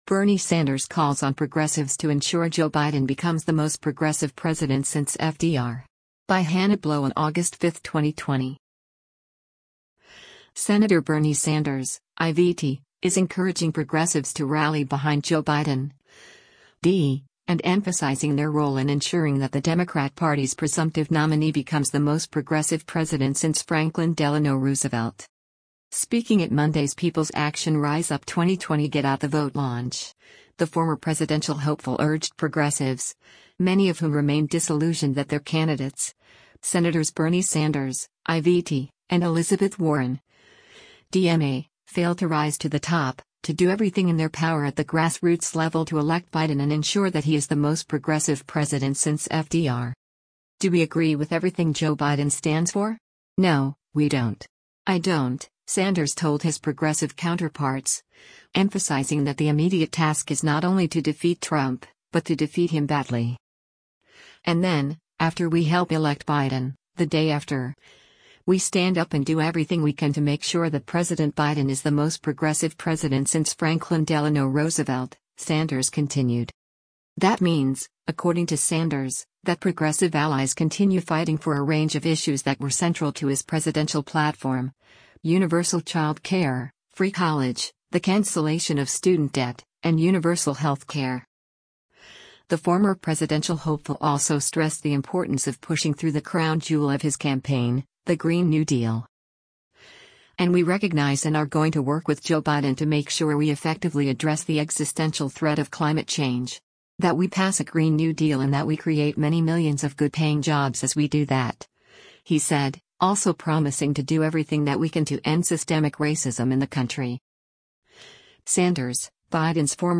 Speaking at Monday’s People’s Action Rise Up 2020 Get Out the Vote launch, the former presidential hopeful urged progressives — many of whom remain disillusioned that their candidates, Sens. Bernie Sanders (I-VT) and Elizabeth Warren (D-MA), failed to rise to the top — to do everything in their power at the grassroots level to elect Biden and ensure that he is the “most progressive” president since FDR: